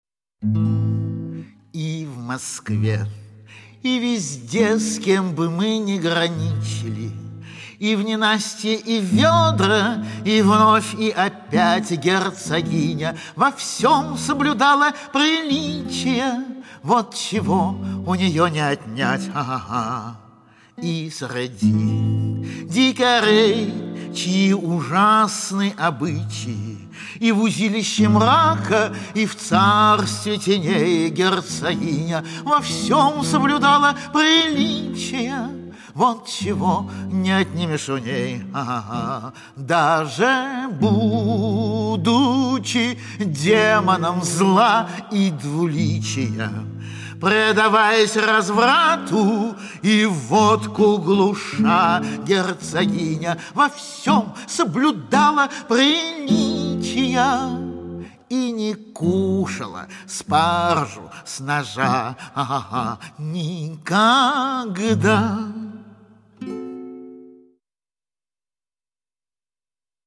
Подражание Вертинскому: грассирование, прононс в нос и т.п.